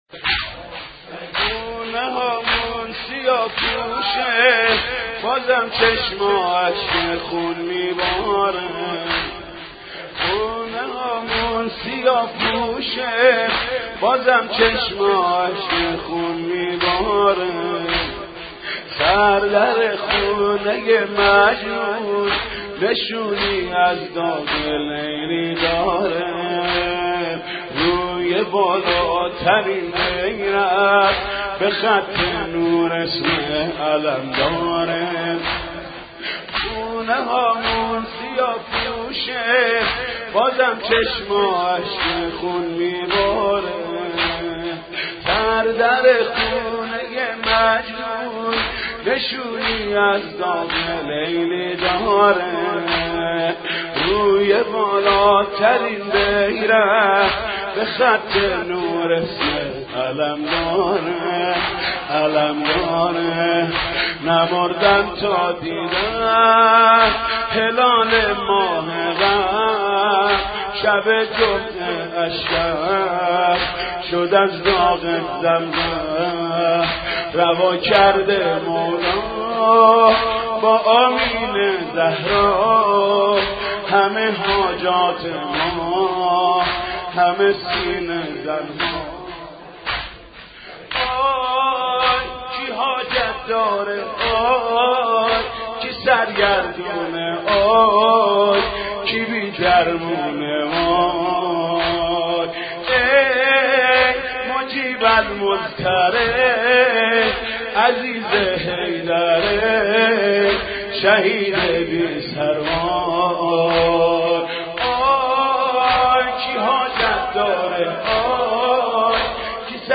مداحی محرم >>>حاج محمود کریمی
حاج محمود کریمی-ذالوا من دمی نغمه القرامی (واحد عربی)